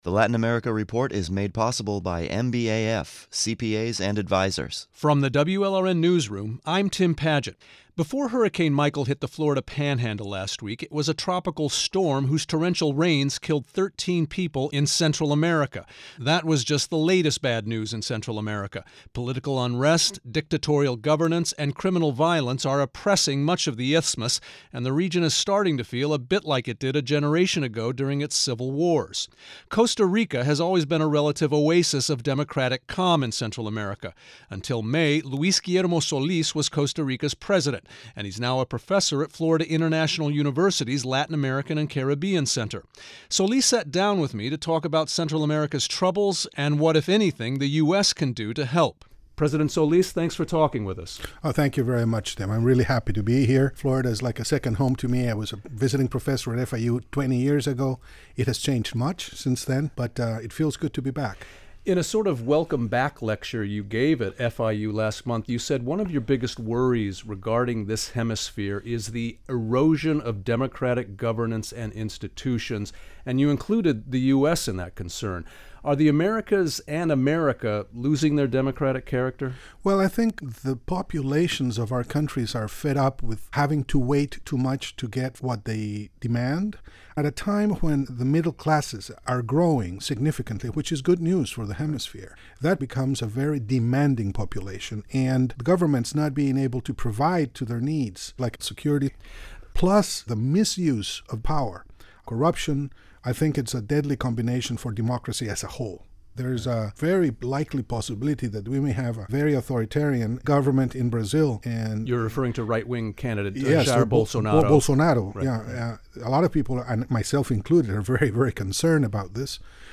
Here are excerpts from their conversation: